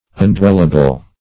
Meaning of undwellable. undwellable synonyms, pronunciation, spelling and more from Free Dictionary.
Search Result for " undwellable" : The Collaborative International Dictionary of English v.0.48: Undwellable \Un*dwell"a*ble\, a. Uninhabitable.